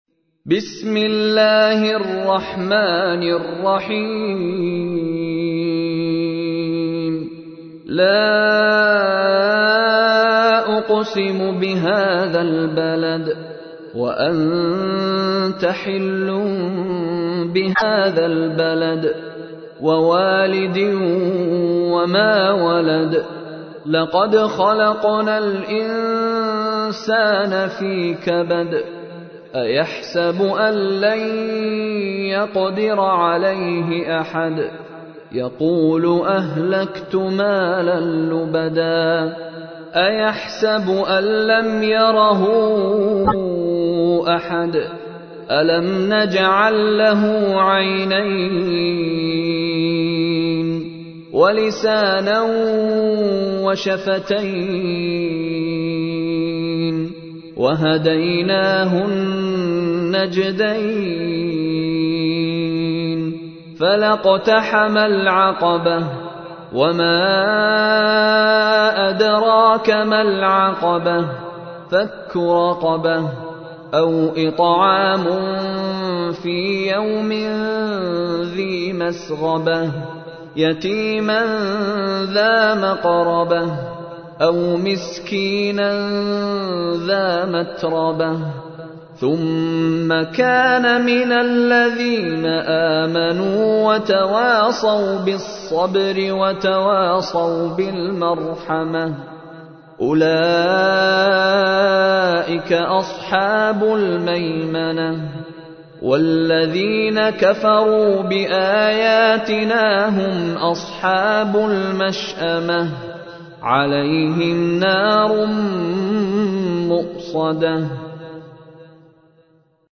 تحميل : 90. سورة البلد / القارئ مشاري راشد العفاسي / القرآن الكريم / موقع يا حسين